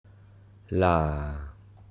ToneMidLowFallingHighRising
Phoneticlaalàalâaláalǎa